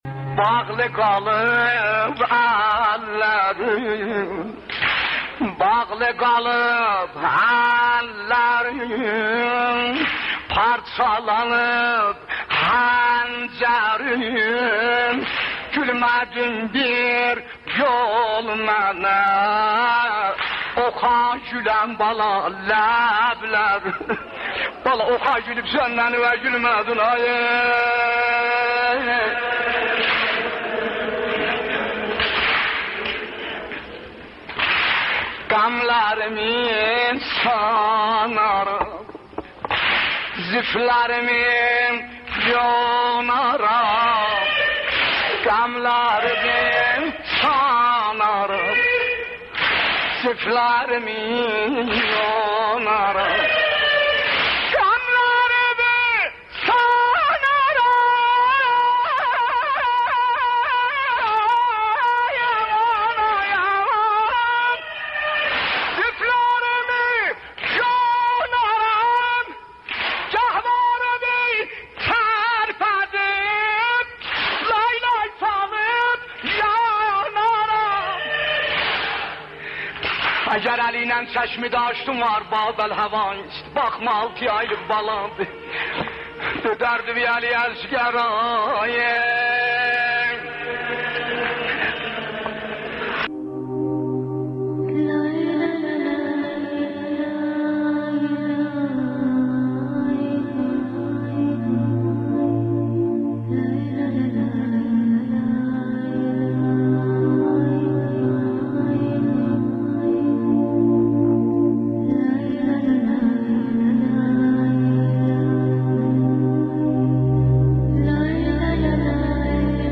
Məhərrəm Nəvası-1 Növhə +(Audio)